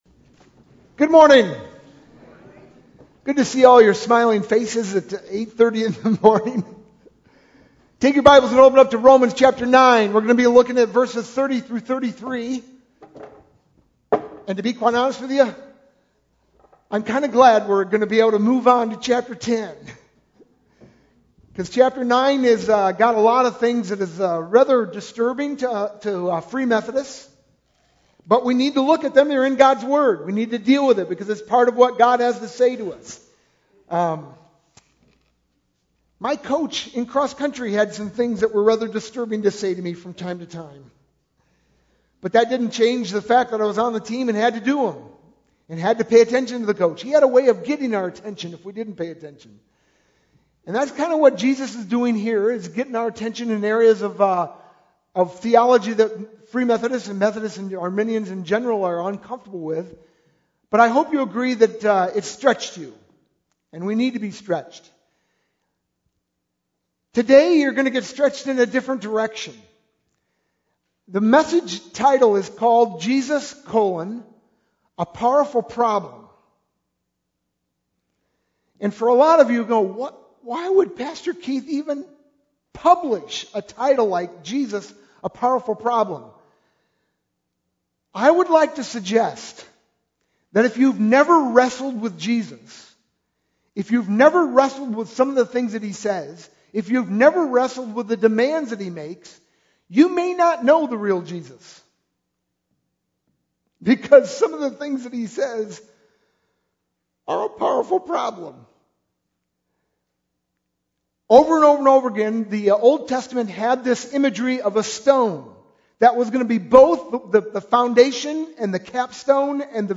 sermon-8-28-11.mp3